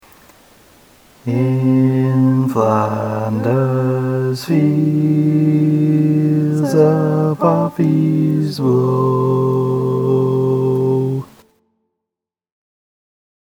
Key written in: F Major
Nice gentle 4-part suspension-chord tag